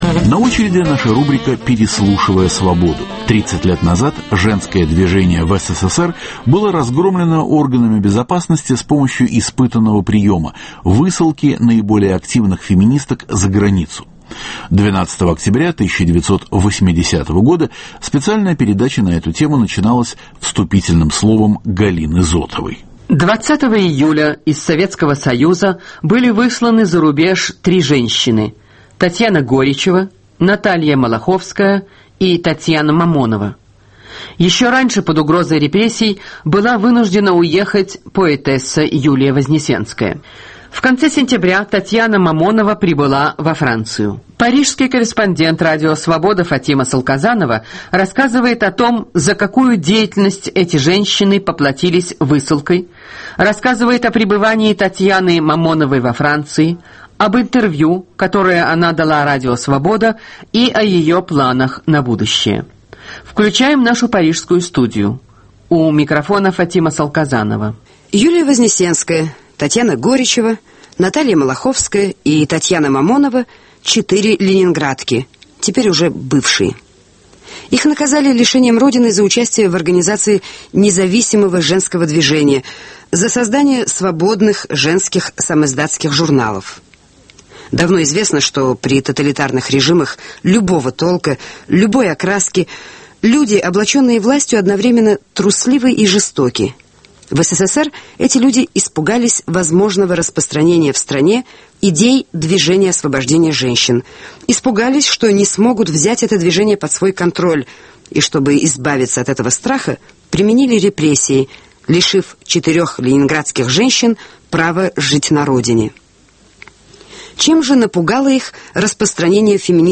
Переслушивая Свободу: Женское движение в СССР, интервью